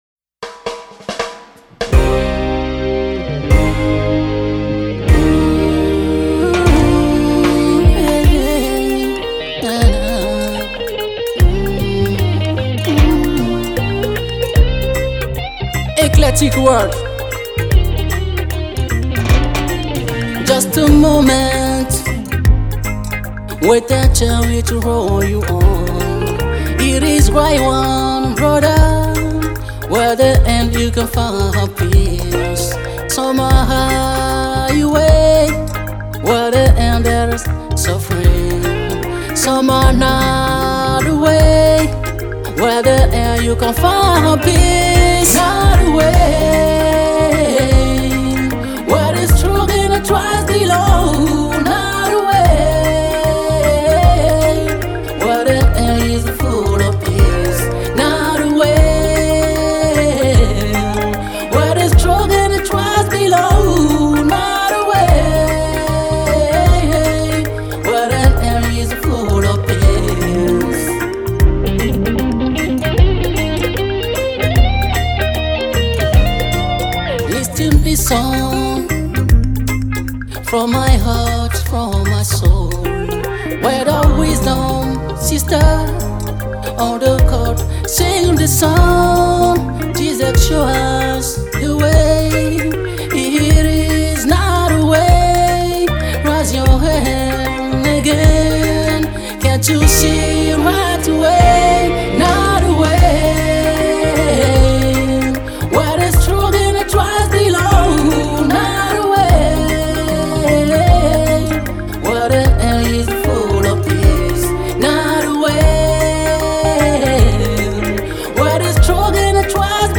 beautiful reggae rendition